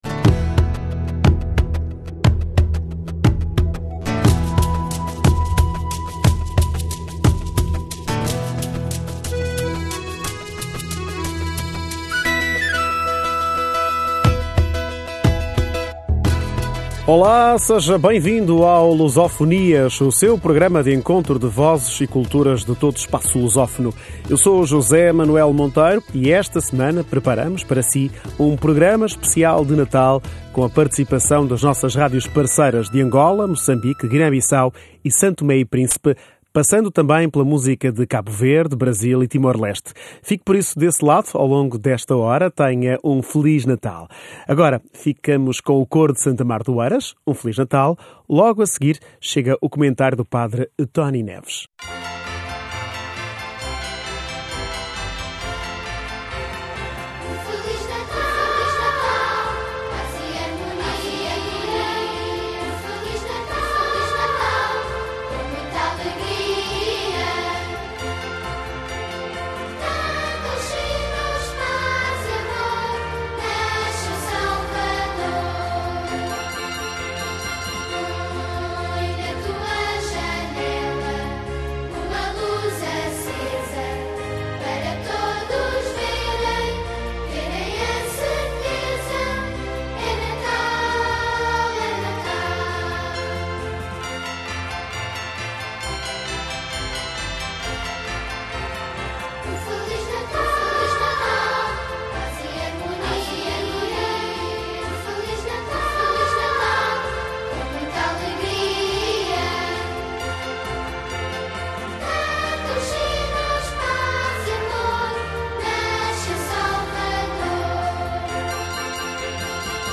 Este Luso Fonias é dedicado ao Natal. Preparámos um programa especial de Natal, com a participação das nossas rádios parceiras de Angola, Moçambique,Guiné-Bissau e de São Tomé e Príncipe, passando também pela música de Cabo Verde, Brasil e Timor-Leste.